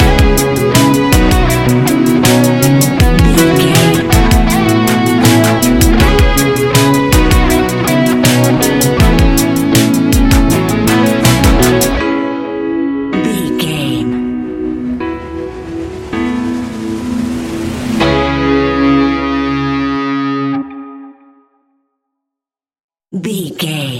Ionian/Major
C♯
ambient
chill out
downtempo